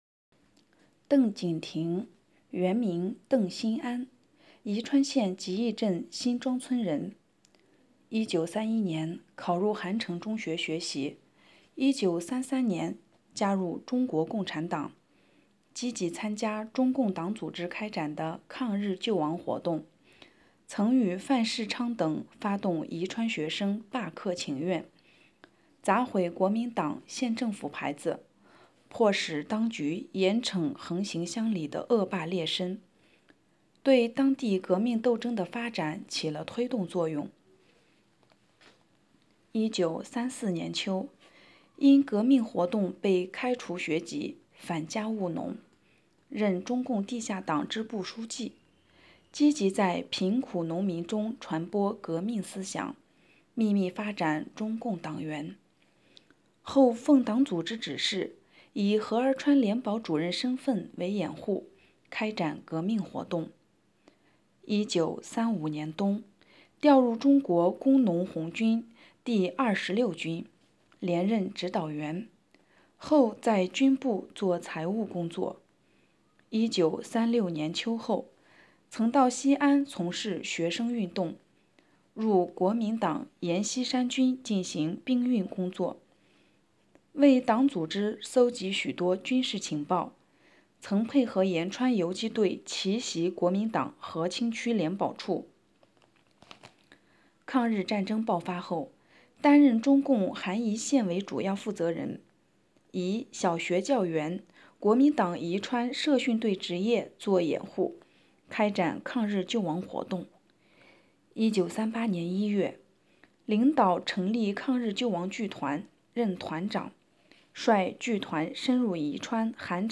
【红色档案诵读展播】宜川革命英烈——邓景亭